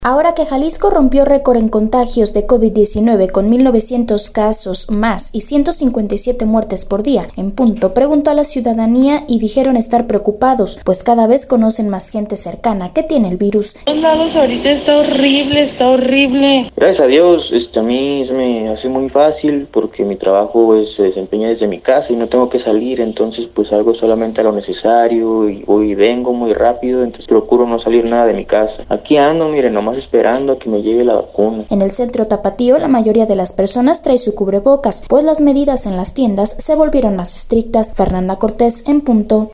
Ahora que Jalisco rompió récord en contagios de Covid-19, con mil 900 casos más y 157 muertes por día, En Punto, preguntó a la ciudadanía y dijeron estar preocupados, pues cada vez conocen más gente cercana que tiene el virus.